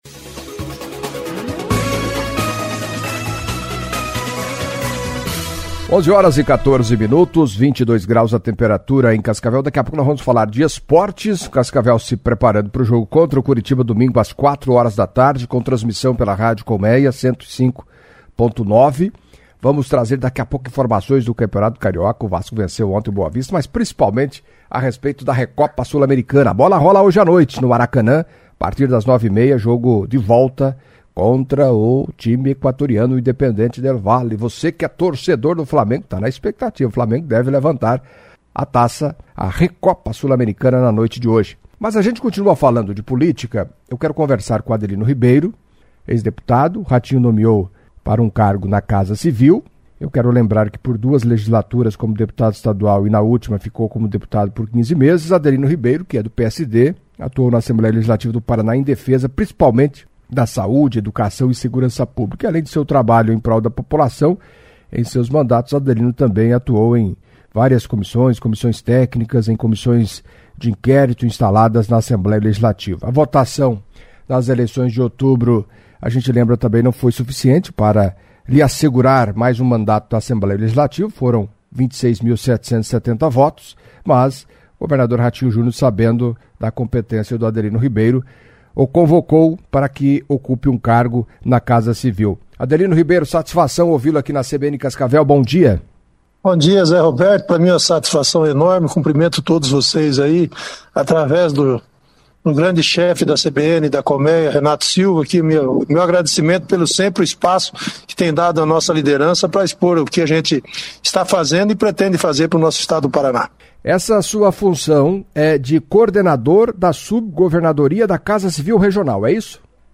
Em entrevista à CBN Cascavel nesta terça-feira (28) Adelino Ribeiro, ex-deputado, destacou o trabalho e os desafios na segunda gestão do governador Ratinho Júnior.